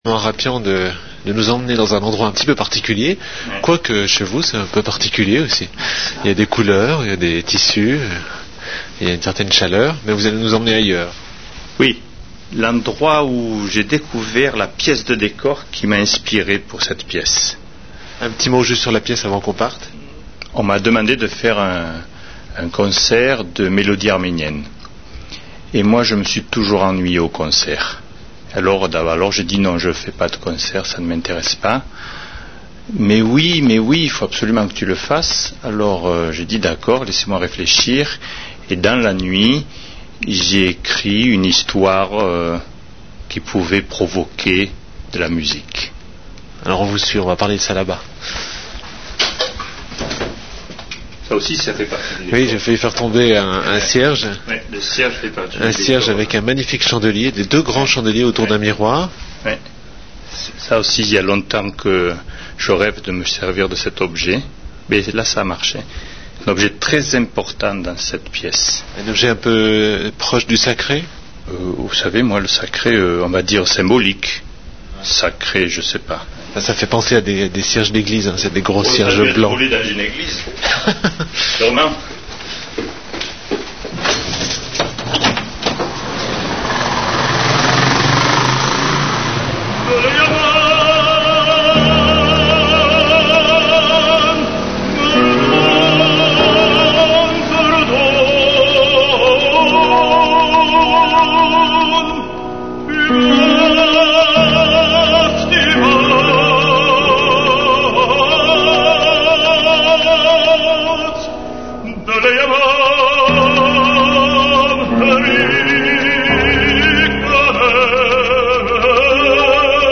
Emission Espace 2 RTS, entretien